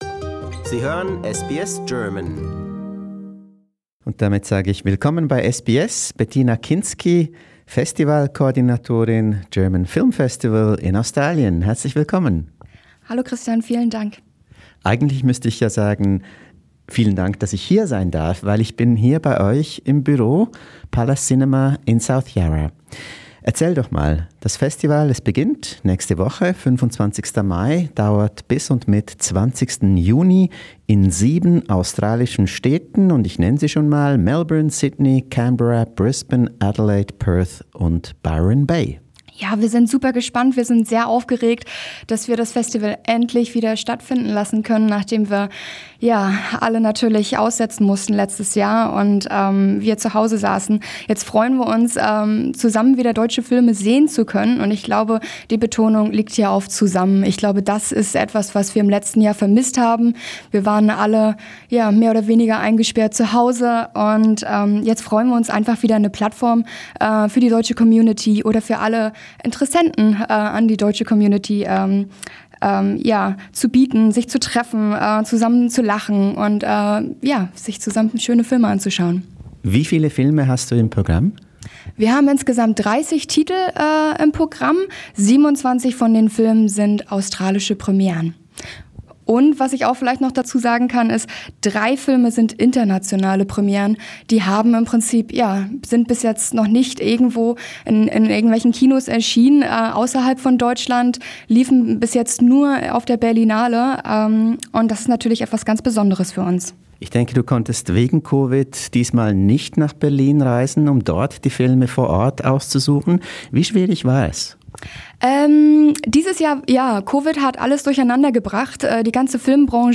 The German Film Festival 2021 in Sydney, Melbourne, Canberra, Brisbane, Adelaide, Perth and Byron Bay inspires with a great program. More on this in a SBS interview